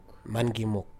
MUNN-gee-mook
If you know IPA: [mangimʊk]